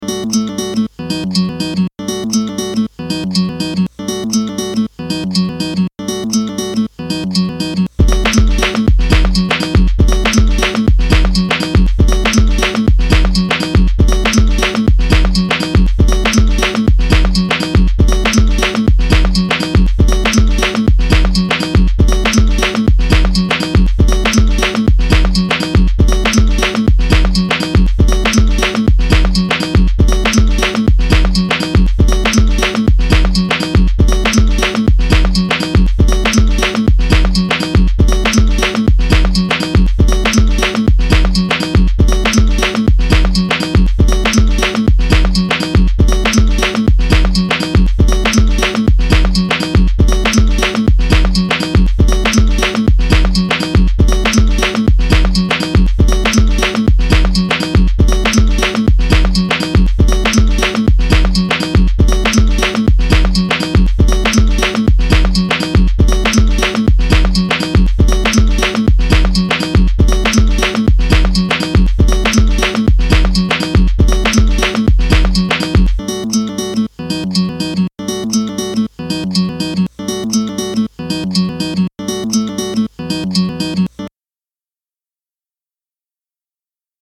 ダンス、エレクトロニカ、テクノ、ヒップホップ